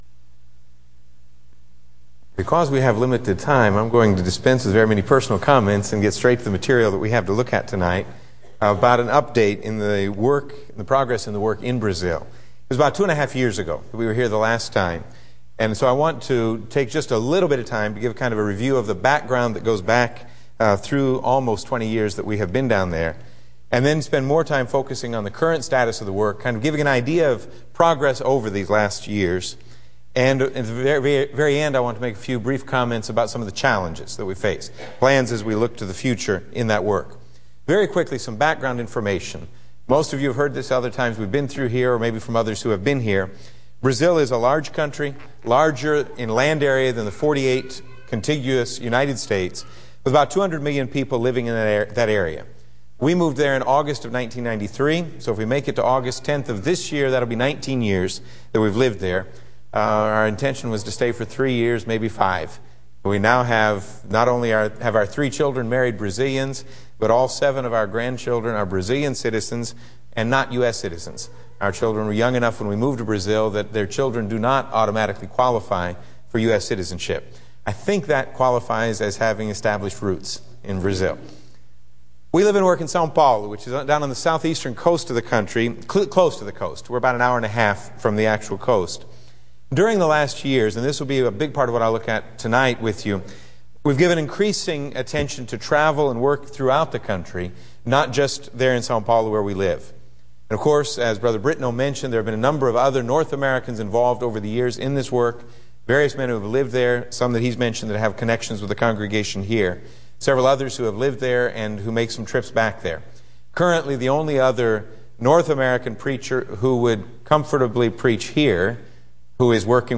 Service: Wed Bible Study Type: Sermon